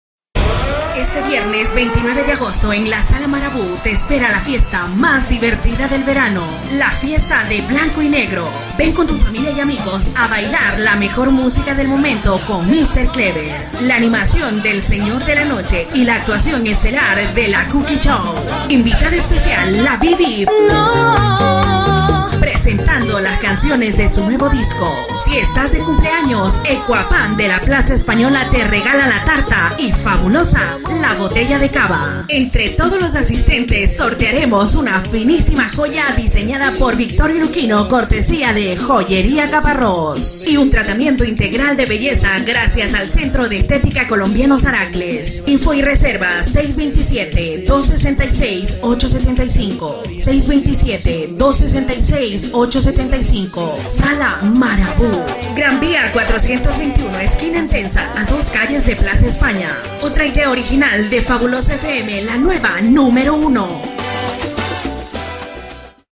Publicitat festa Blaco y negro a la sala Malabú, amb identificació final de la ràdio